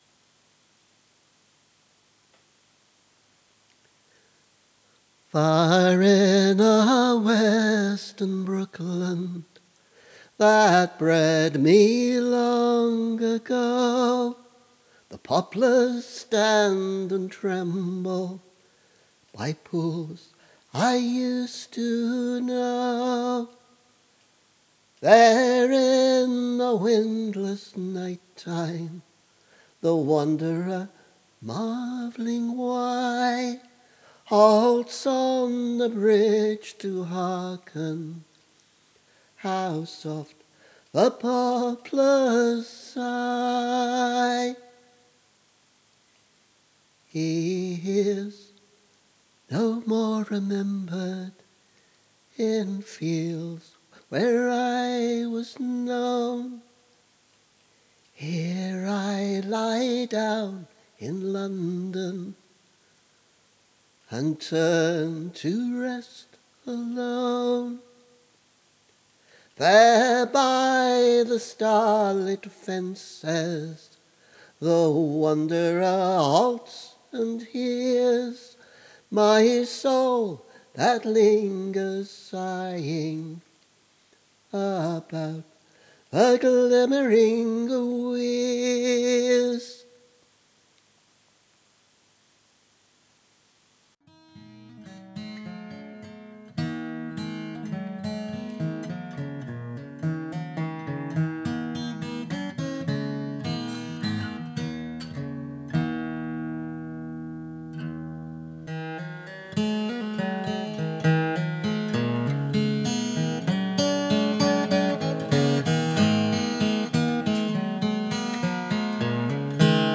A work in progress. Now includes two vocal settings of verse from ‘A Shropshire Lad’ separated by a guitar solo.